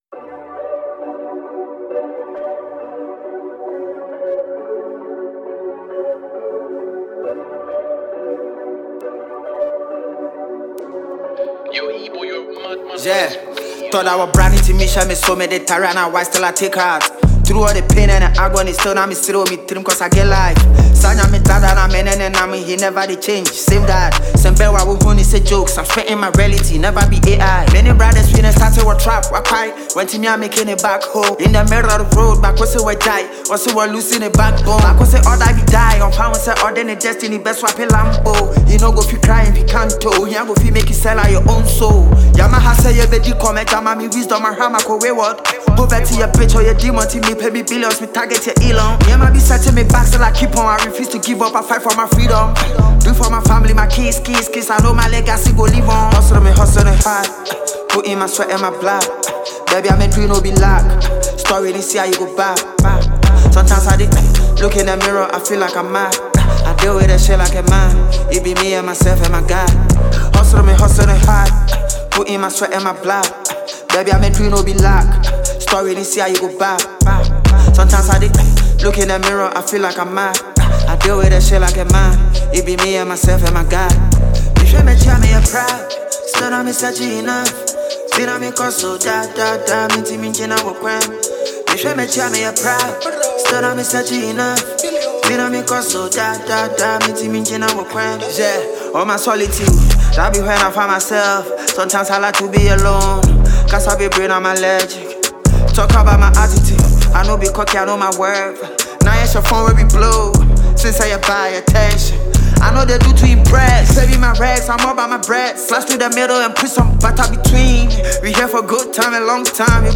a reflective and timeless piece